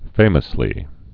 (fāməs-lē)